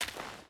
Dirt Walk 2.ogg